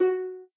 harp.ogg